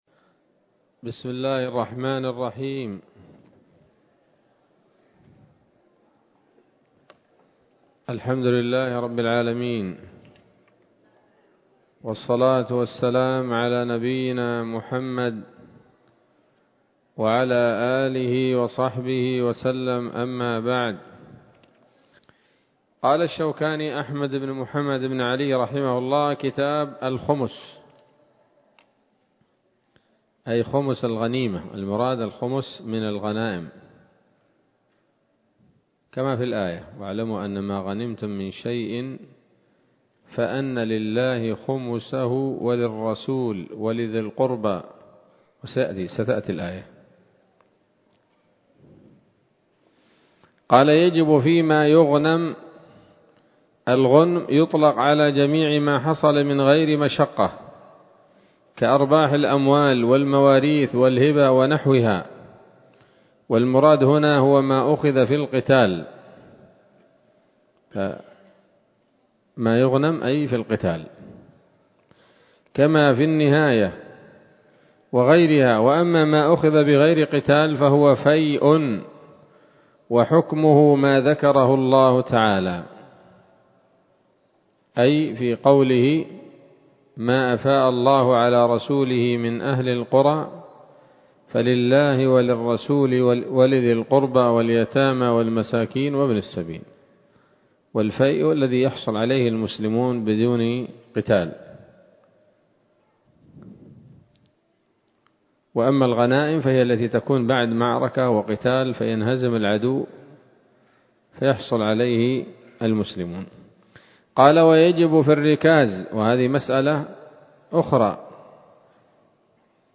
الدرس العاشر وهو الأخير من كتاب الزكاة من السموط الذهبية الحاوية للدرر البهية